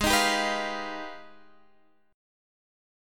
Listen to G#mM13 strummed